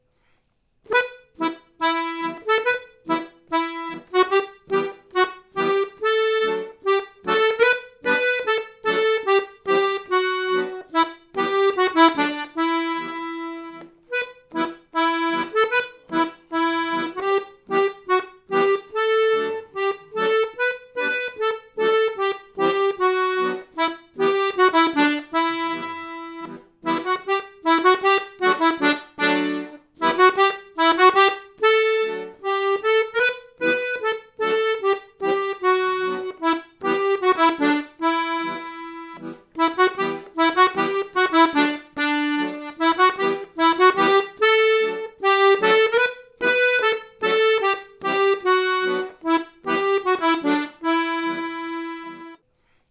Den Grundbass spielen wir nur auf 1, auf 3 lassen wir ihn weg.
In der Begleitung wechseln sich immer D-Dur und E-Moll ab.